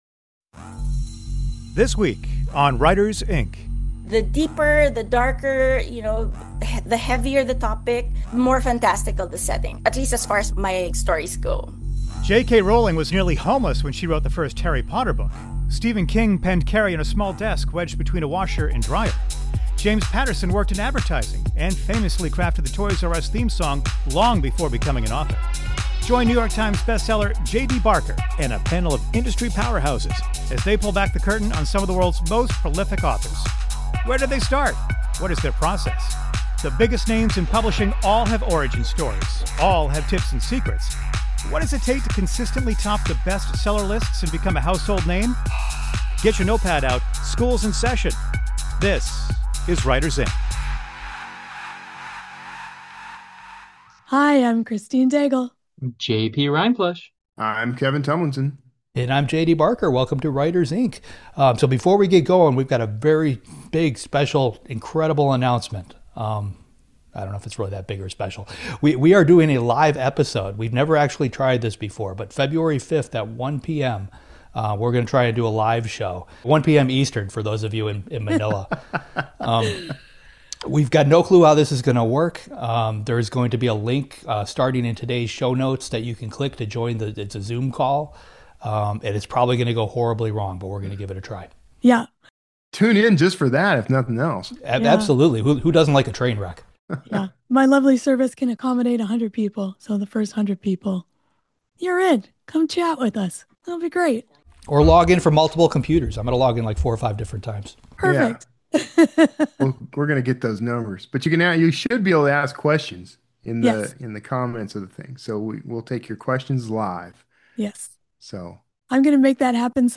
Then, stick around for a chat